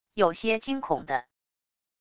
有些惊恐的wav音频